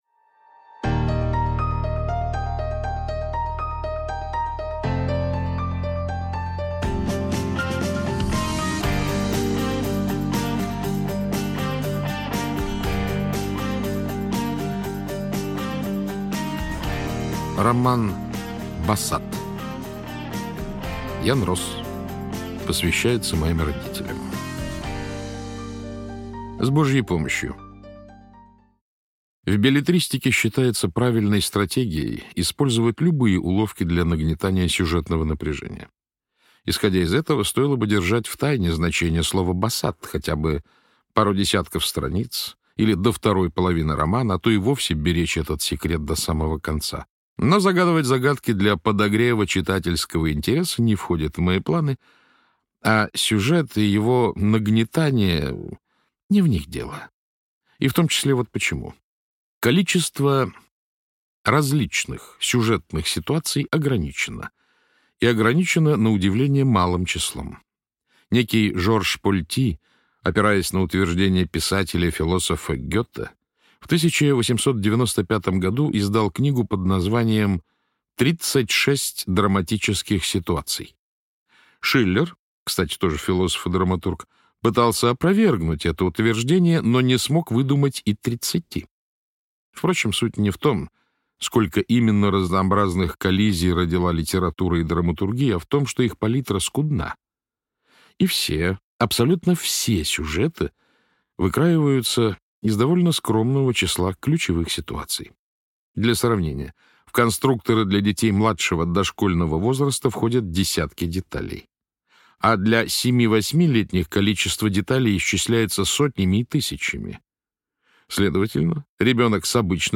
Аудиокнига БАСАД из жанра Проза - Скачать книгу, слушать онлайн